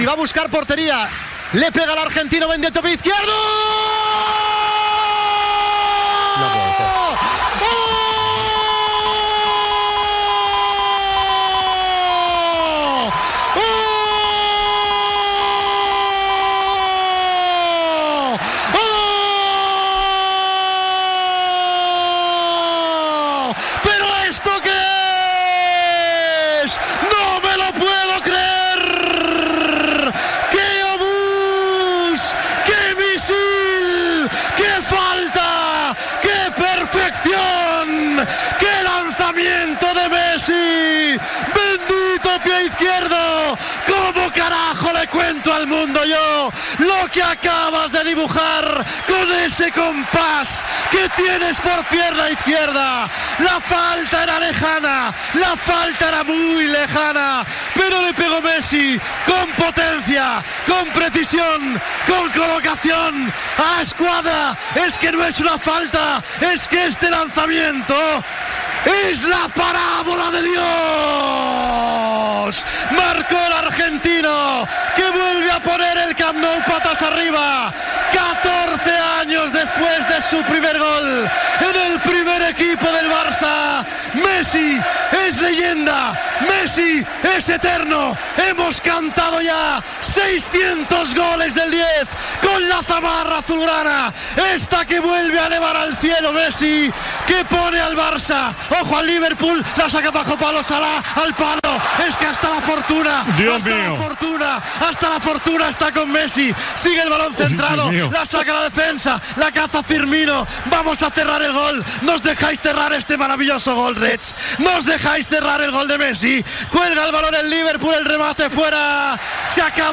Narració del gol de falta de Leo Messi, el número 600 que marca, al partit Futbol Club Barcelona - Liverpool, descrit com "la parábola de Dios", a la semifinal de la Copa d'Europa de Futbol masculí
Esportiu